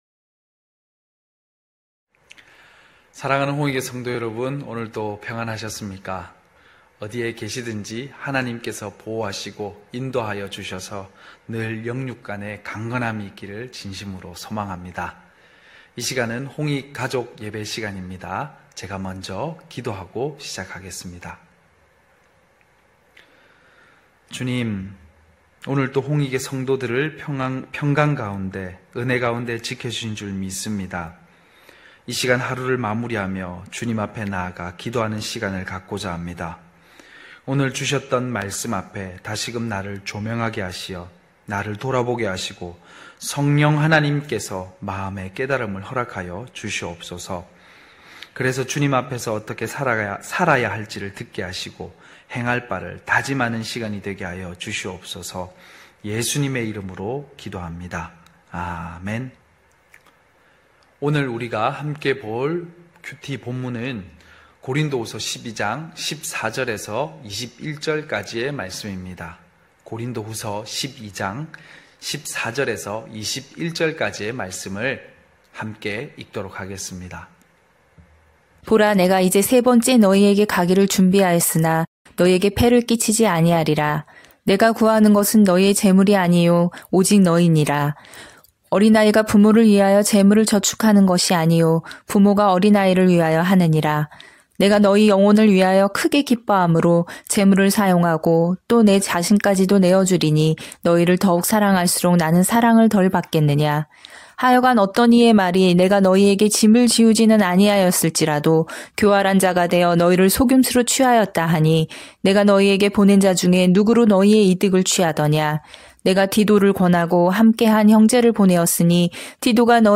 9시홍익가족예배(10월12일).mp3